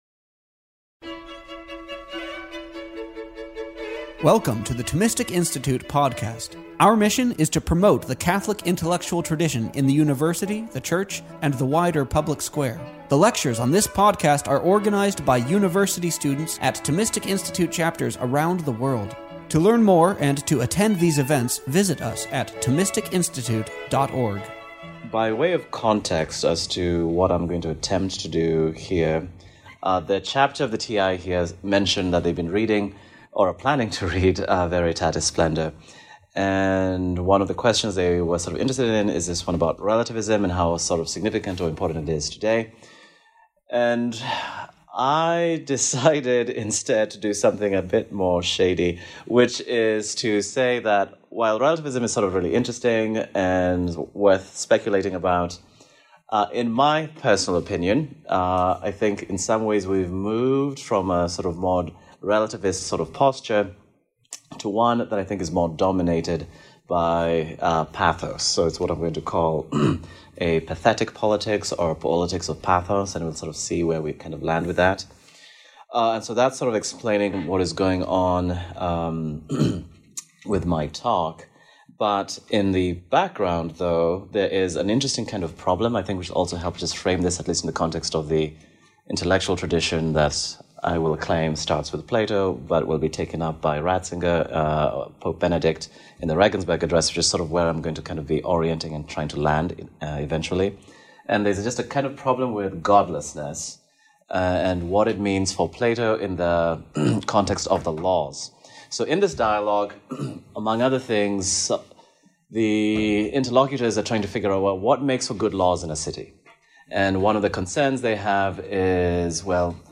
This lecture was given on October 23rd, 2025, at Fordham University.